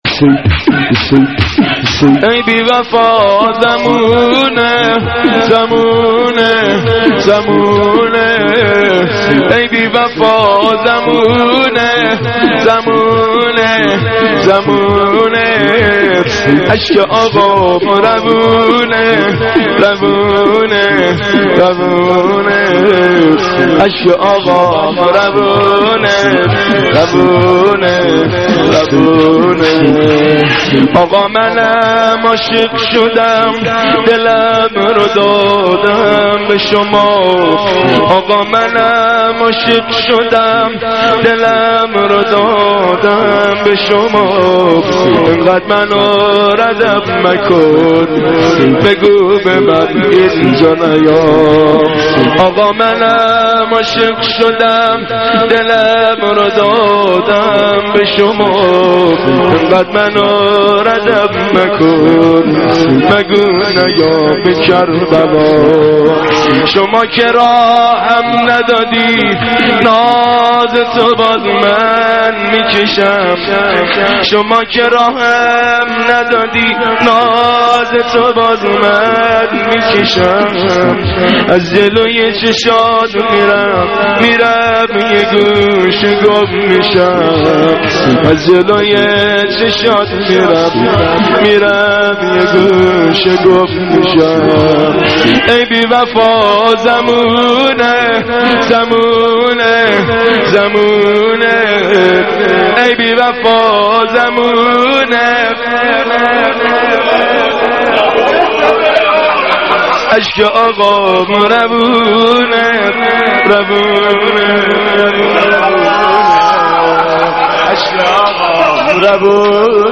(شور - امام حسین علیه السلام)